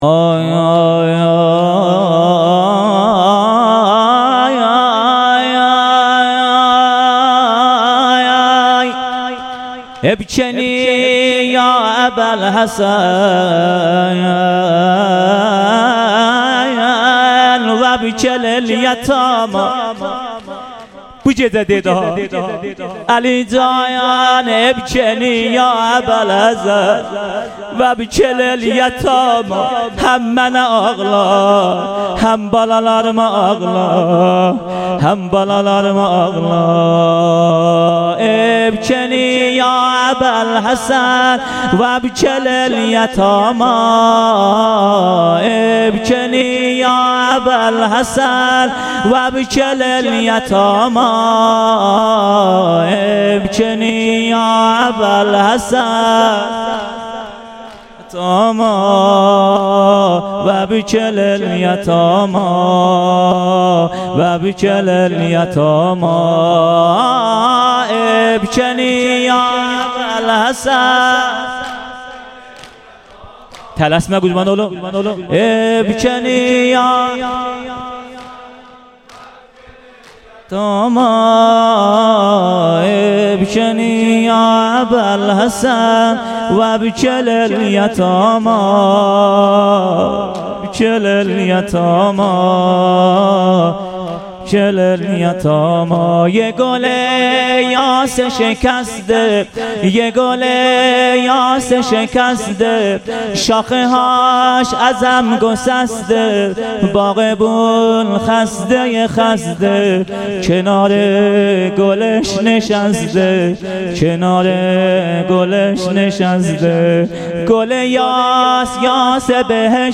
فاطمیه 97 ( فاطمیه دوم) - شب دوم- بخش پایانی سینه زنی و روضه